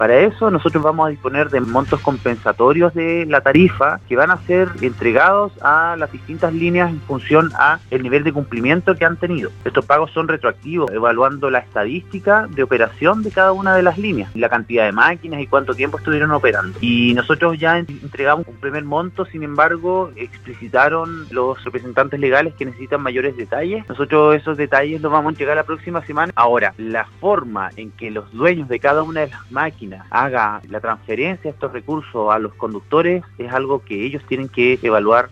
Luego del paro de microbuses de la Línea 7 de Osorno, que se depuso al iniciarse conversaciones entre la empresa y la autoridad regional, el seremi de Transportes y Telecomunicaciones en Los Lagos, Pablo Joost, aclaró en entrevista con Radio Sago algunos de los puntos que considera el Perímetro de Exclusión y el congelamiento de las tarifas de transporte público ordenada por el gobierno para este año.